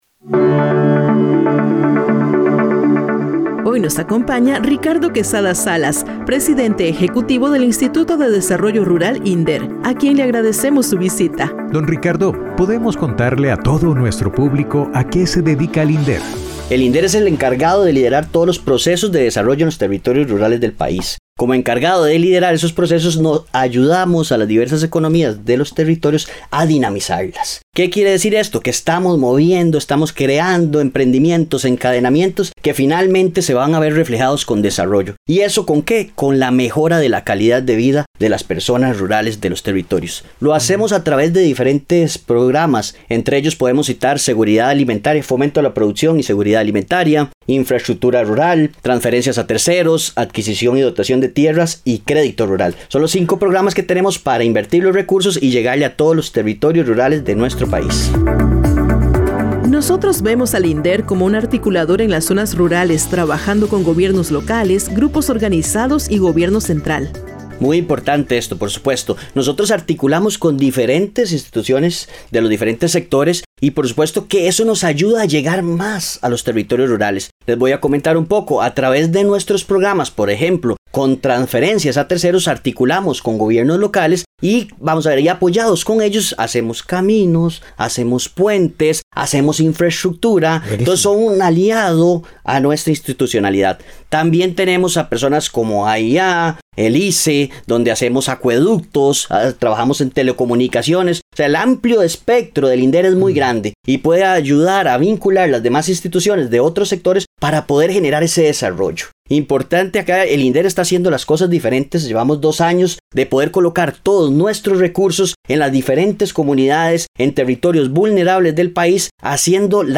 Entrevista a Ricardo Quesada Salas., presidente ejecutivo del INDER